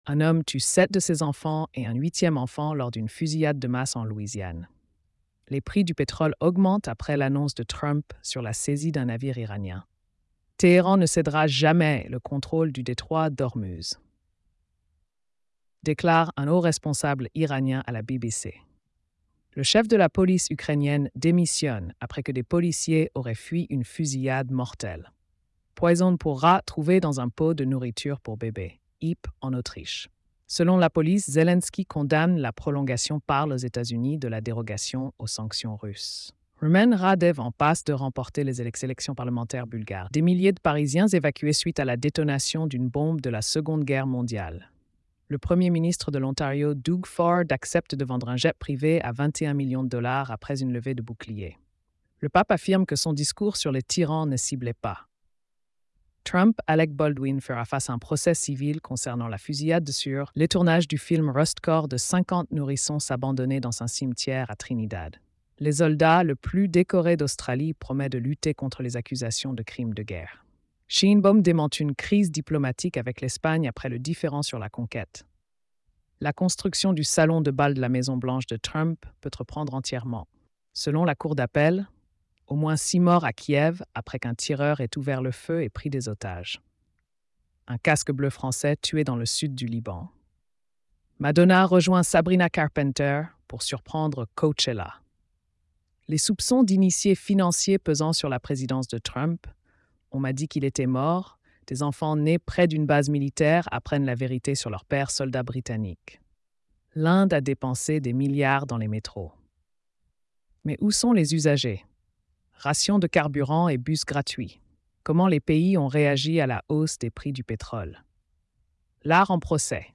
🎧 Résumé des nouvelles quotidiennes.